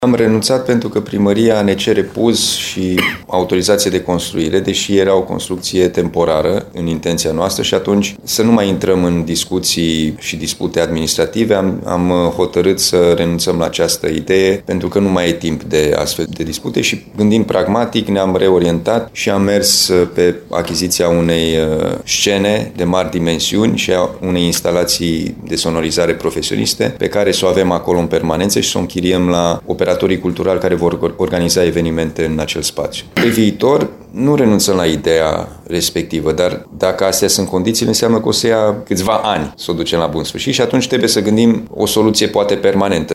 În schimb, administrația județeană va cumpăra o scenă pe care să o închirieze operatorilor culturali, explică președintele CJ Timiș, Alin Nica.